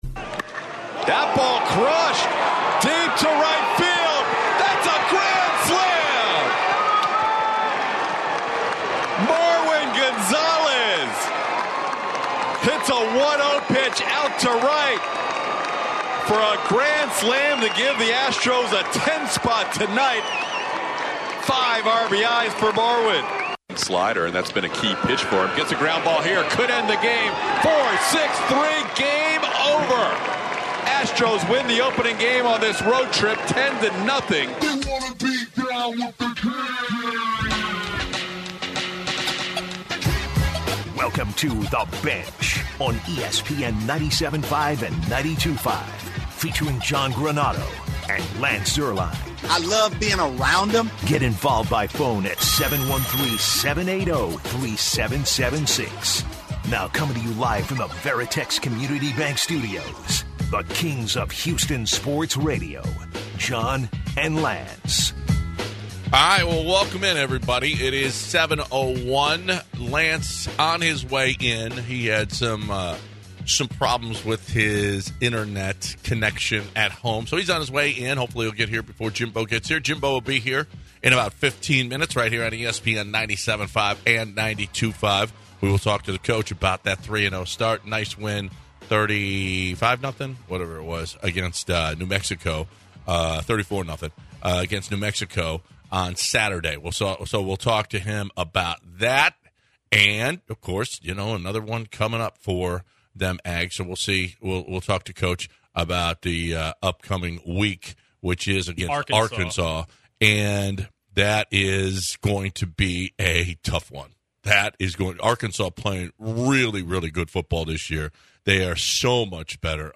To start out today's show the guys talk the Astros blow out win last night over the Angels. Early on in the hour Aggies head coach Jimbo Fisher joins and discusses his start to the season and some upcoming games.